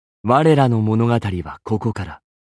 文件 文件历史 文件用途 全域文件用途 巴形开始游戏.mp3 （MP3音频文件，总共长2.5秒，码率64 kbps，文件大小：20 KB） 巴形开始游戏语音 文件历史 点击某个日期/时间查看对应时刻的文件。